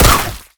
spitter-death-3.ogg